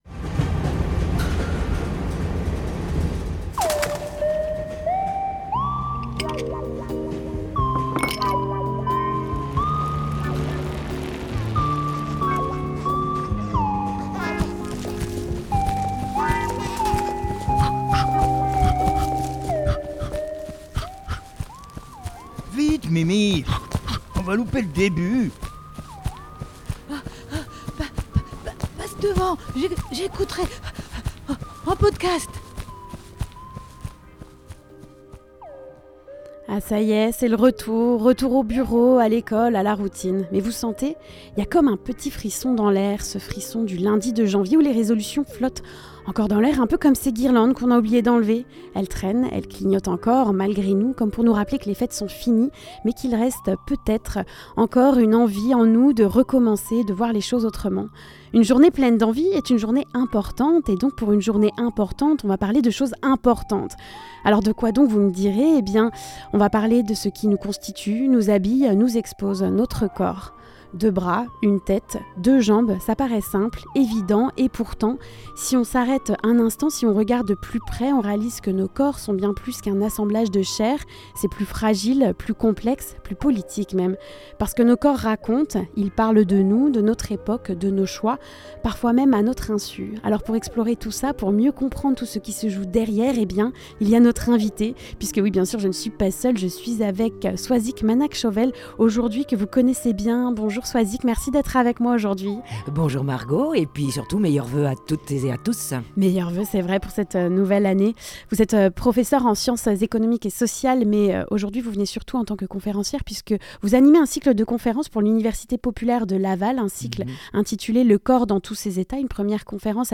La revue de presse du Haut Anjou